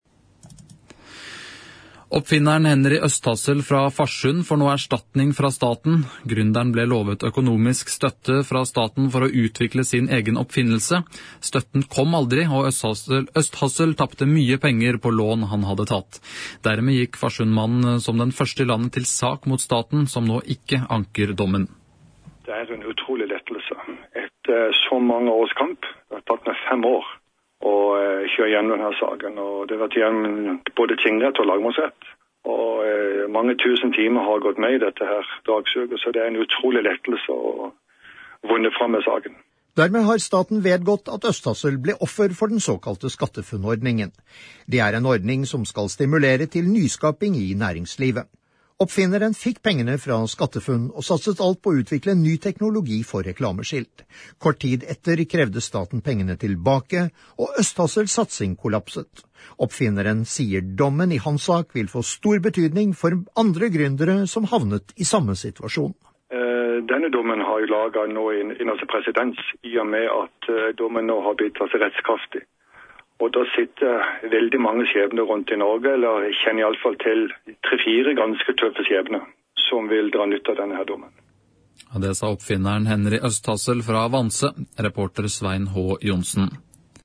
Morgensending med innslag